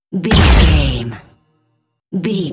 Dramatic hit laser
Sound Effects
Atonal
heavy
intense
dark
aggressive